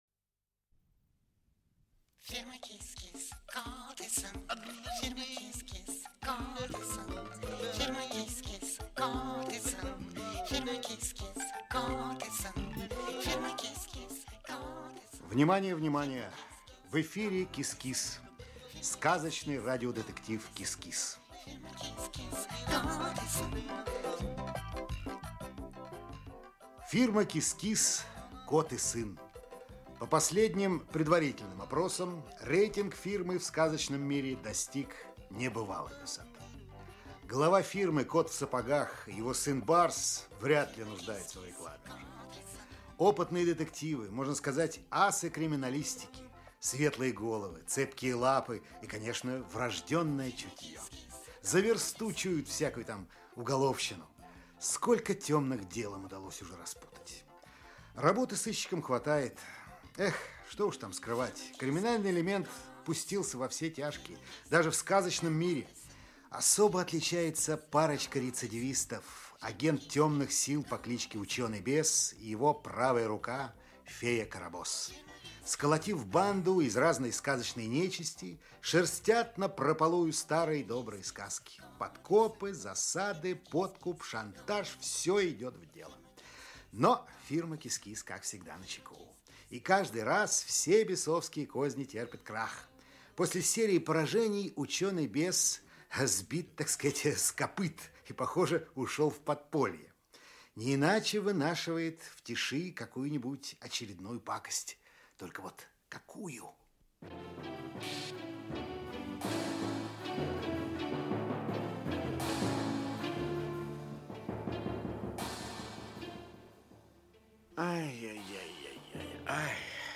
Аудиокнига КИС-КИС. Дело № 7. Часть 1 | Библиотека аудиокниг
Часть 1 Автор Зоя Чернышева Читает аудиокнигу Александр Леньков.